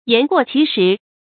yán guò qí shí
言过其实发音
成语正音其，不能读作“qī”。